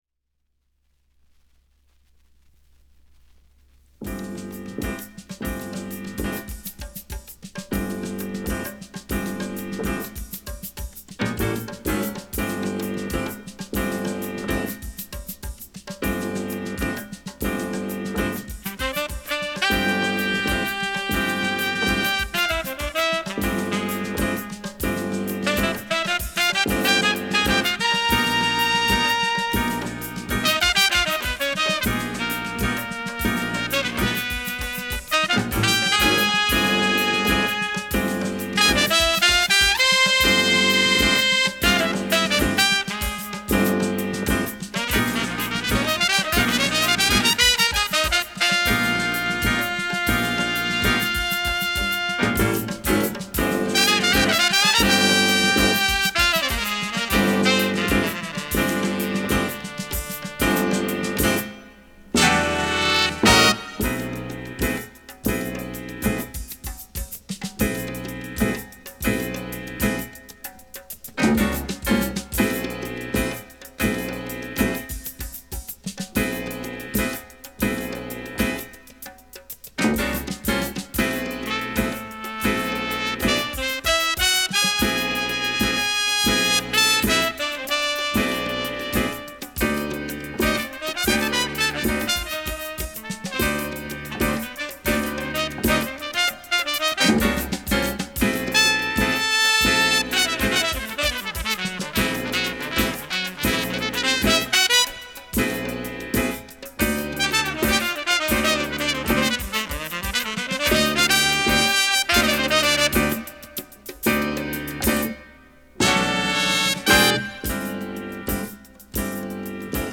(Jazz)